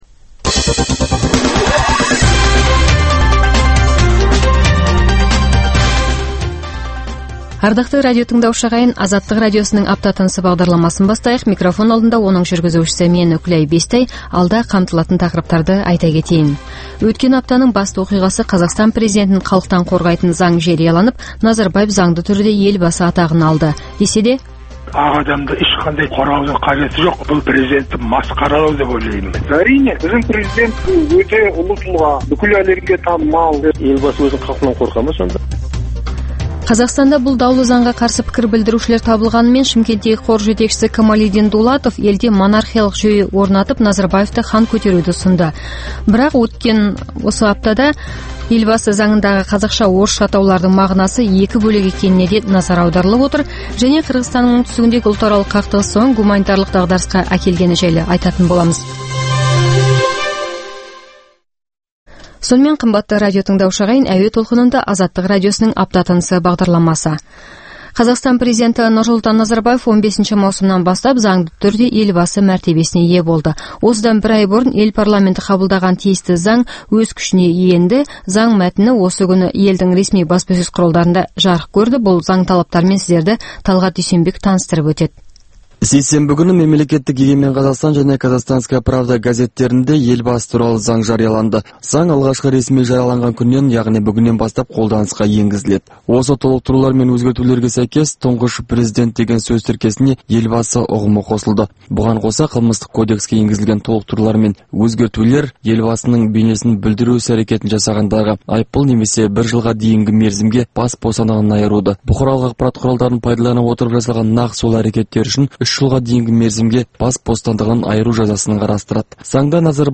Апта тынысы – Апта бойына орын алған маңызды оқиға, жаңалықтарға құрылған апталық шолу хабары.